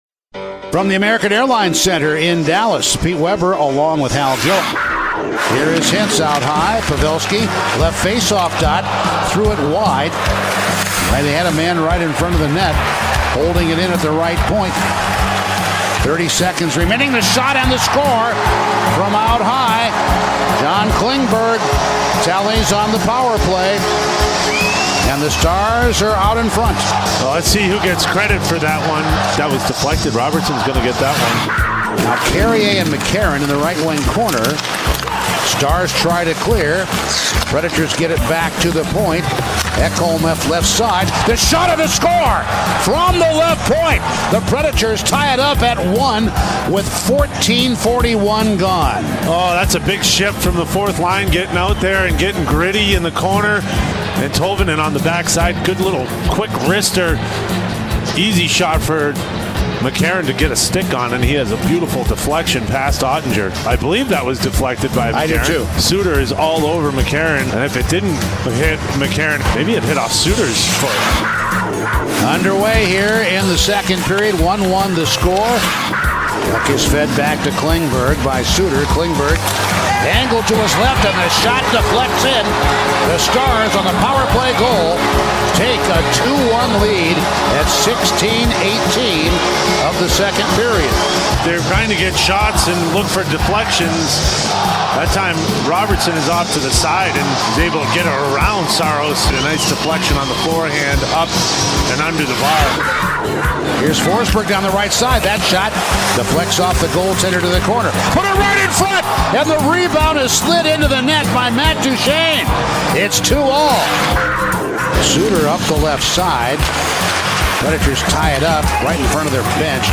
Radio highlights from the Preds' 4-3 loss in Dallas